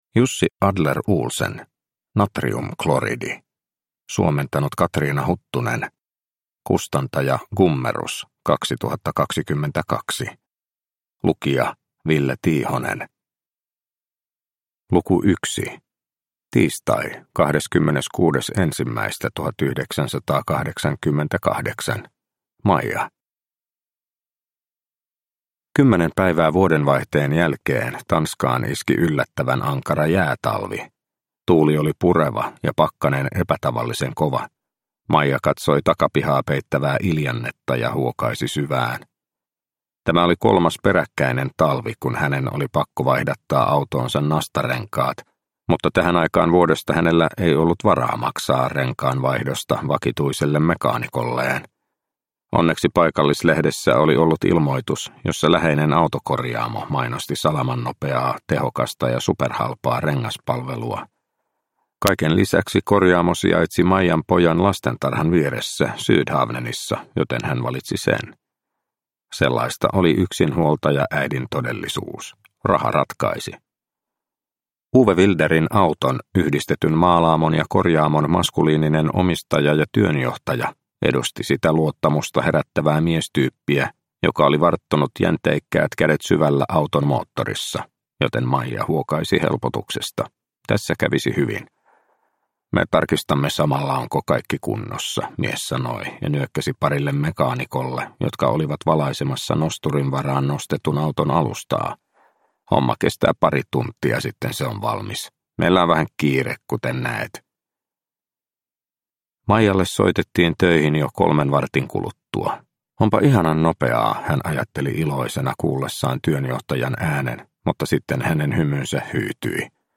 Natriumkloridi – Ljudbok – Laddas ner